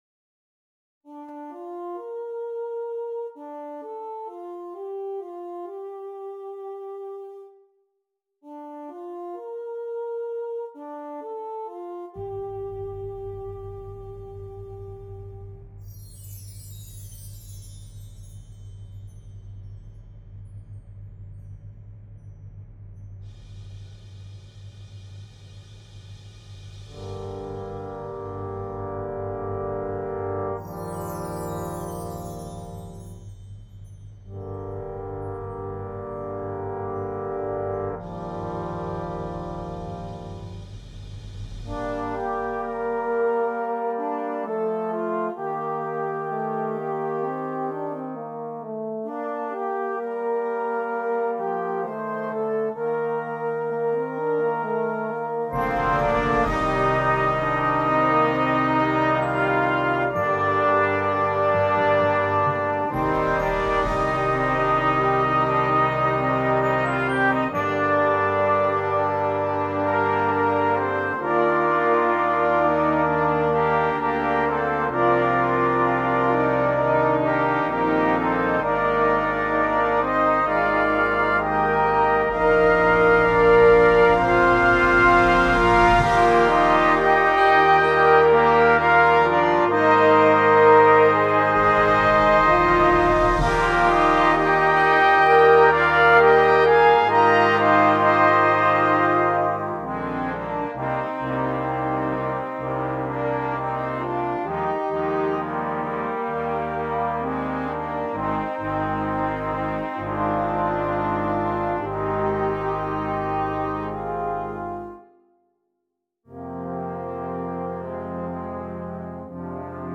Voicing: 12 Brass and Percussion